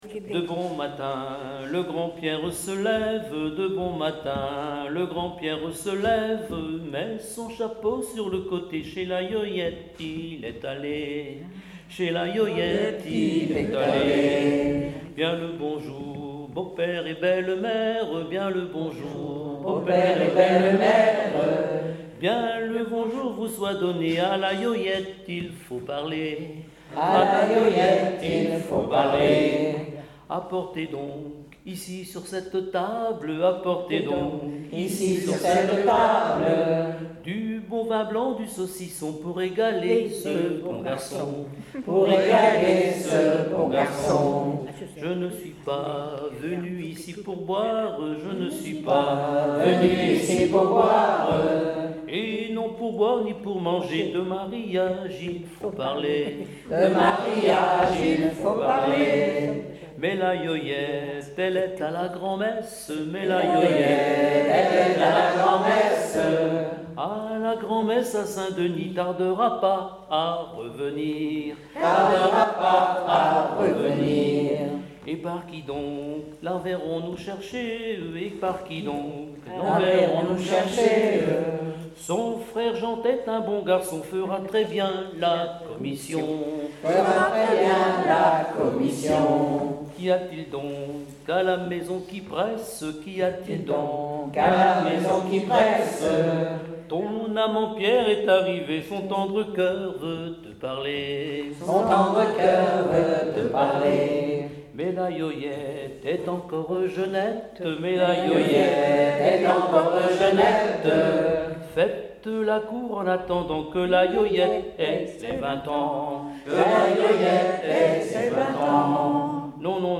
Genre strophique
enregistrement de veillées en chansons
Pièce musicale inédite